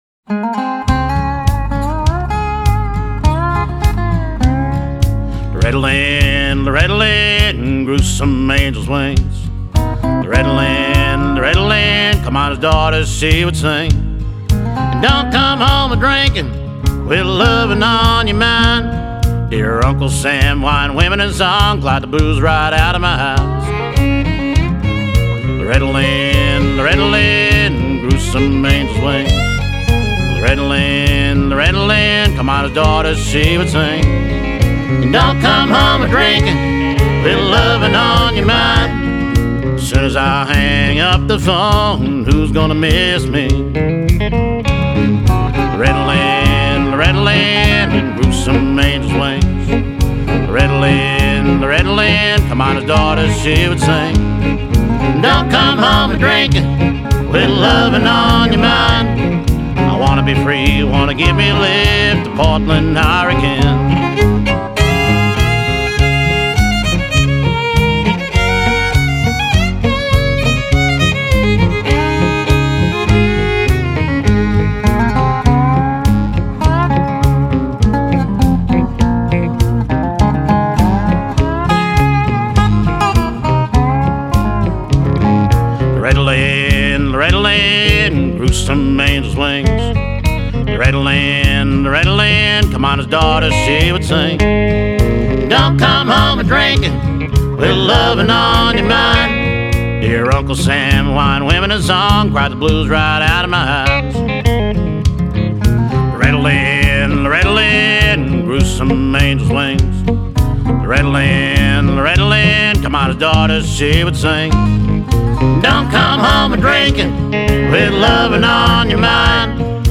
fiddle
acoustic & electric guitars, bass, dobro & harmony vocals.